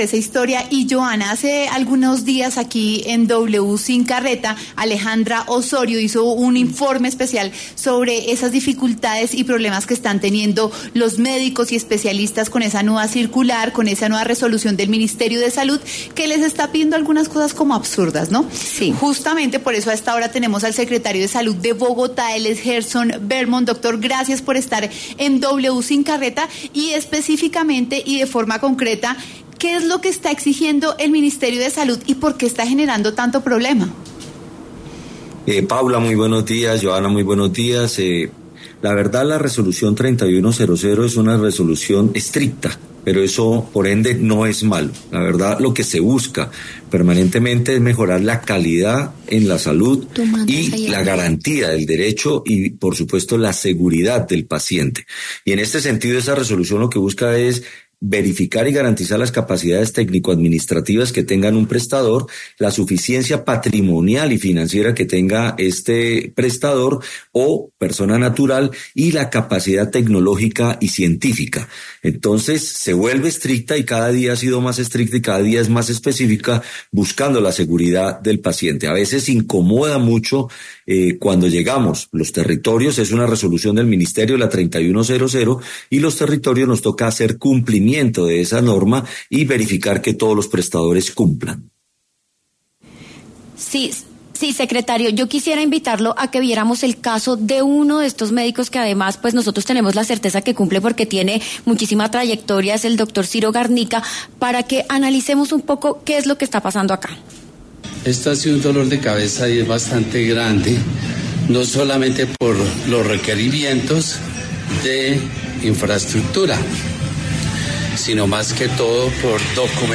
Gerson Bermont, secretario de Salud de Bogotá, conversó con W Sin Carreta sobre las denuncias de doctores sobre exigencias excesivas para trabajar en sus consultorios médicos.